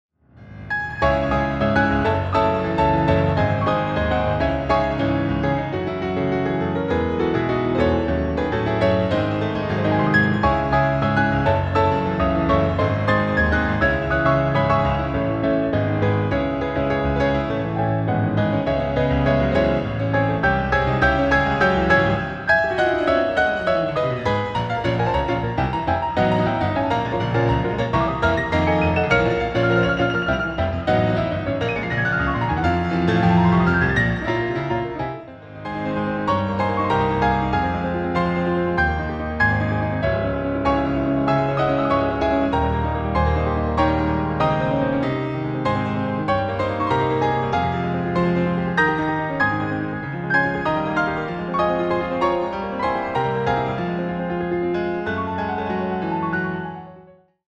ピアノ連弾試聴